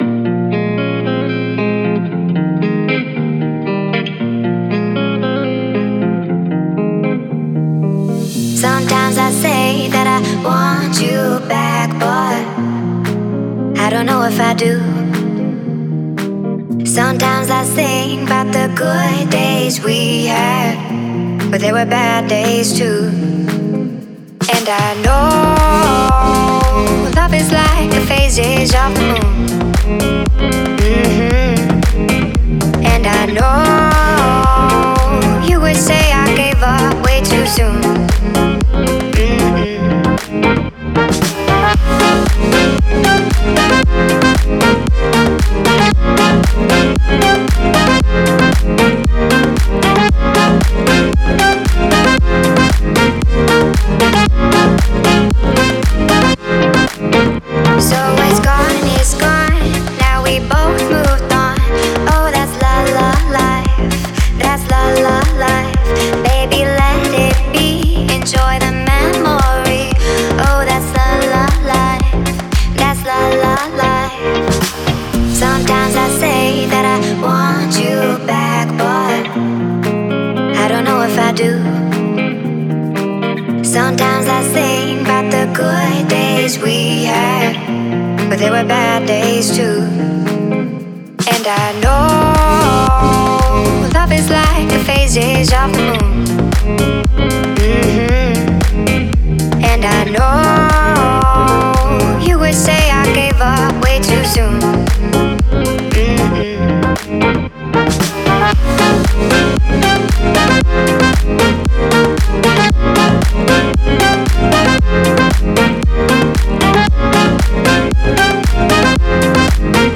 это энергичная поп-песня
наполненная позитивом и жизнеутверждающим настроением.